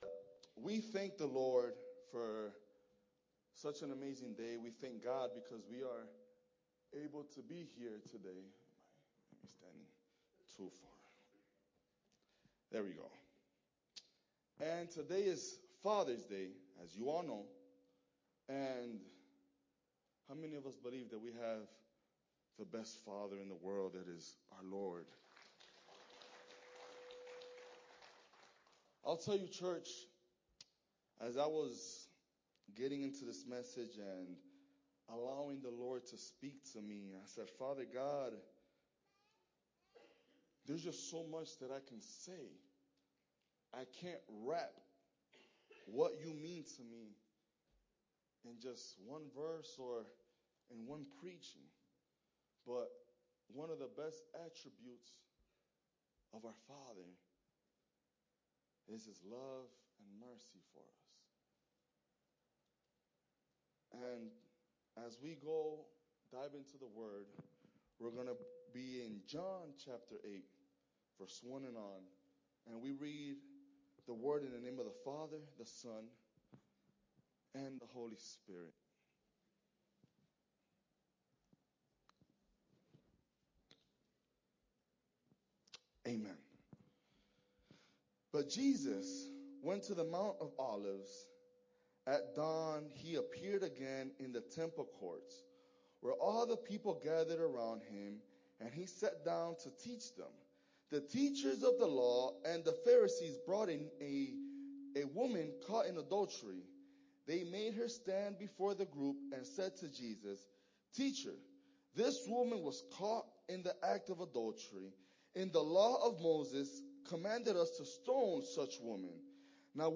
Father’s Day Service